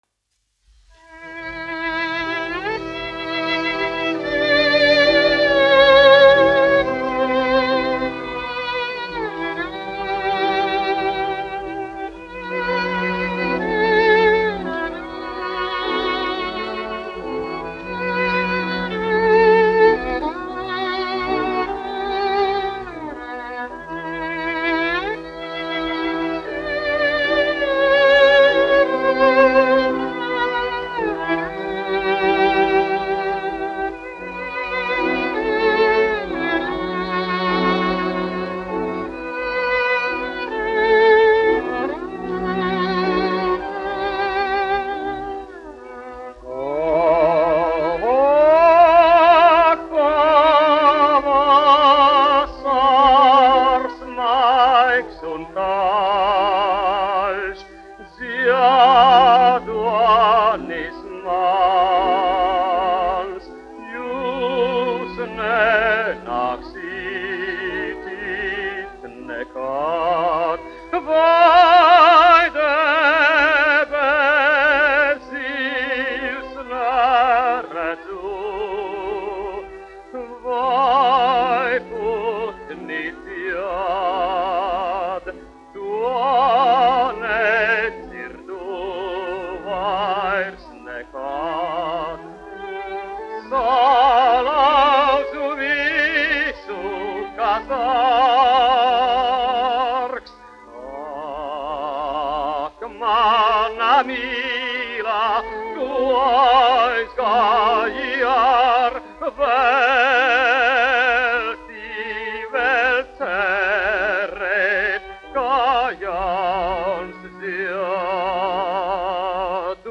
Priednieks-Kavara, Artūrs, 1901-1979, dziedātājs
1 skpl. : analogs, 78 apgr/min, mono ; 25 cm
Dziesmas (augsta balss) ar instrumentālu ansambli
Latvijas vēsturiskie šellaka skaņuplašu ieraksti (Kolekcija)